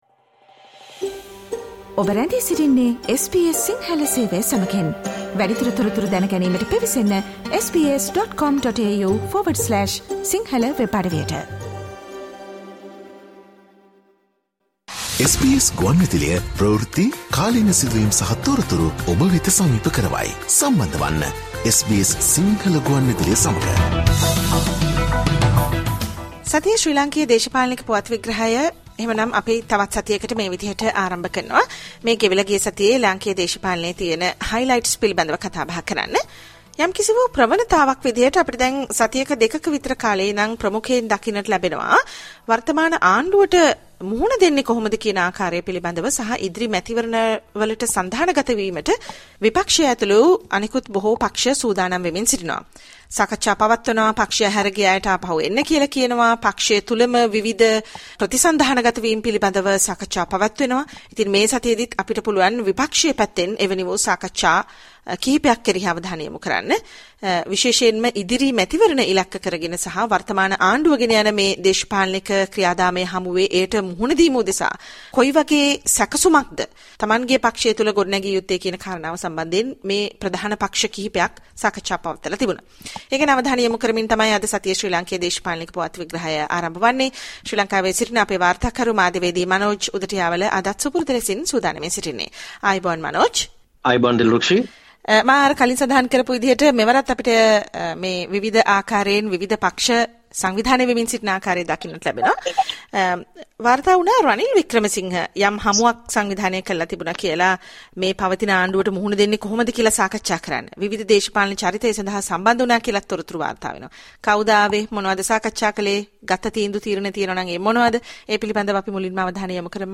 සජීව වැඩසටහනේ කෙටස් දෙකක් ලෙසින් ප්‍රචාරය වුනු විශේෂාංග එකතු කොට එක වැඩසටහනක් ලෙසට වෙබ් අඩවියට එක්කොට ඇති බව කරුණාවෙන් සලකන්න.